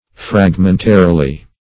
Meaning of fragmentarily. fragmentarily synonyms, pronunciation, spelling and more from Free Dictionary.
fragmentarily.mp3